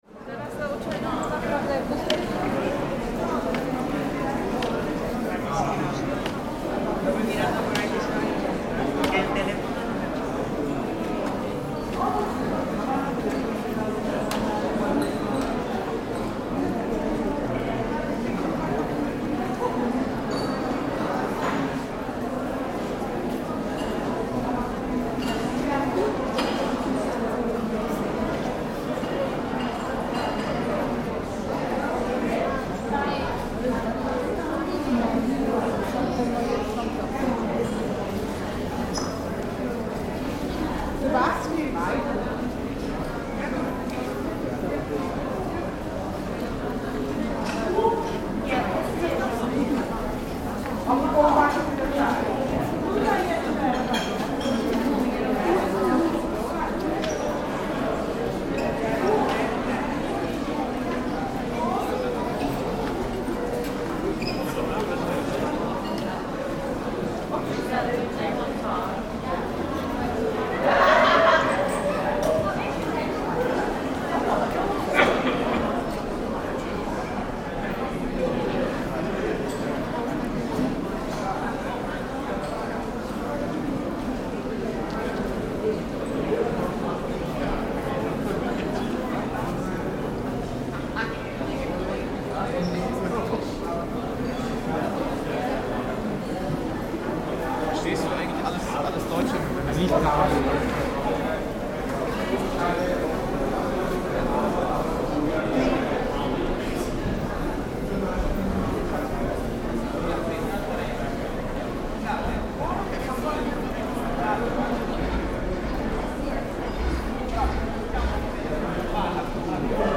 In the recording, the heart of Kraków’s Market Square comes alive with the dynamic sounds of a lively marketplace. Happy crowds weave through the market, searching for deals, while the rhythmic footfalls of eager buyers punctuate the energy of the square.
UNESCO listing: Historic Centre of Kraków